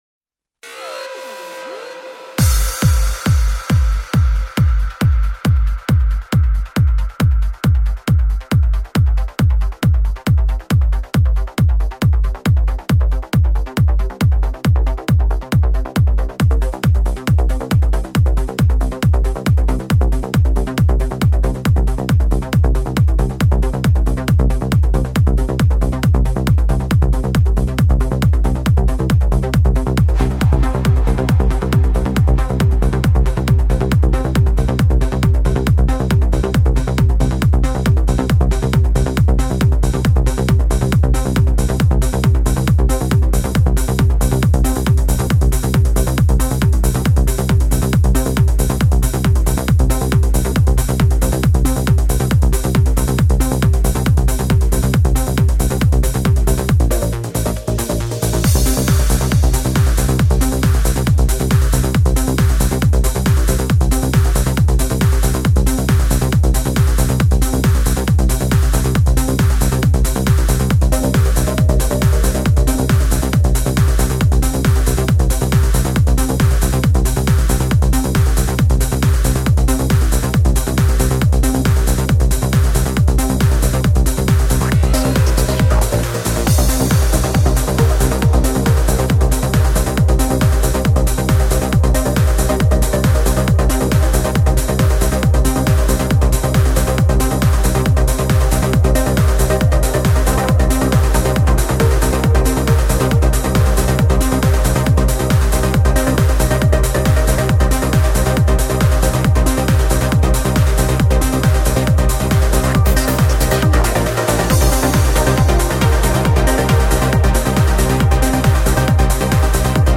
Восхитительно - классный Транс!!!
Подстиль: Uplifting Trance
Позитив | Энергия | Чувство | Ритм | Стиль | Движение